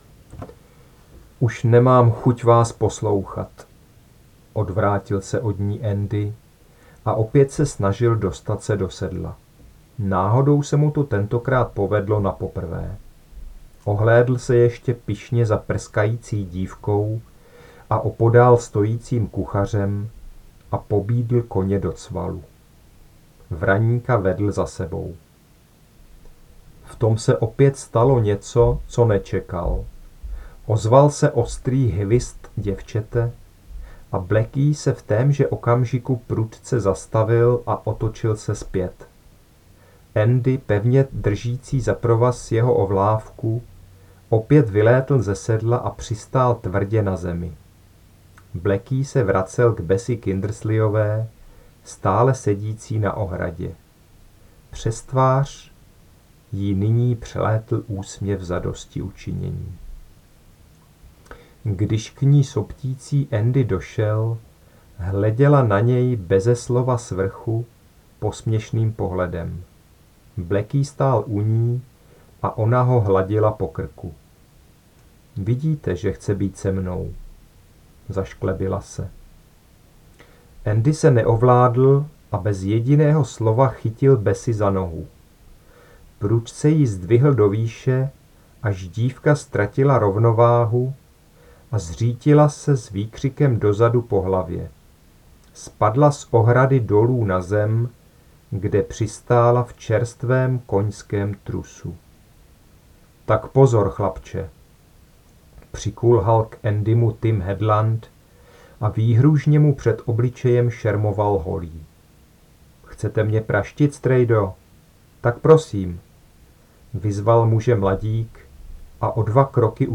• audiokniha v mp3  cena: 160,- Kč (6 €)  (ukázka níže)